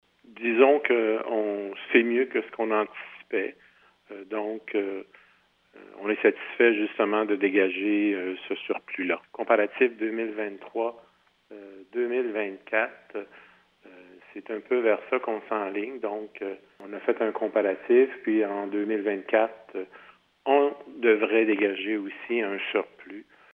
La Ville de Waterloo a déposé ses états financiers pour l’année 2023, mardi soir, lors de la séance mensuelle du conseil municipal.
Le maire de Waterloo, Jean-Marie Lachapelle, a d’ailleurs fait un discours sur la situation financière de la municipalité.